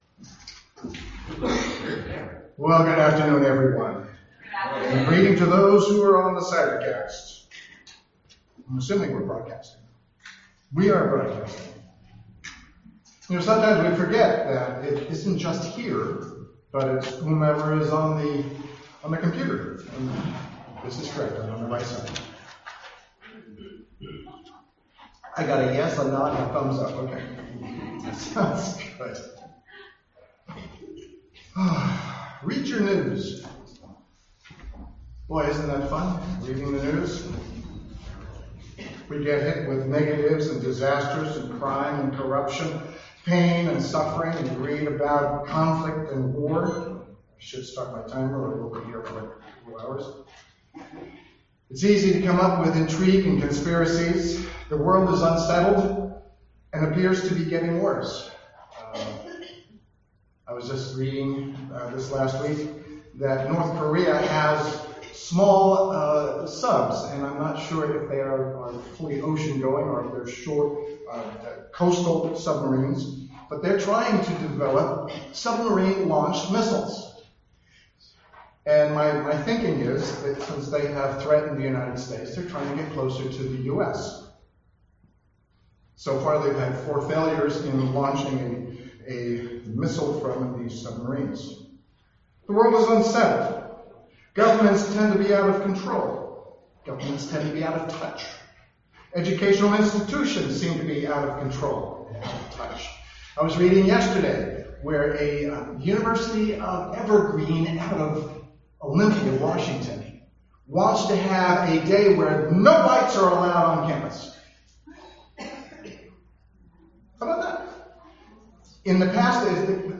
This sermon is about the coming Kingdom of God when the kingdoms of this world will become the kingdom of our Lord, and God will make everything right in the end.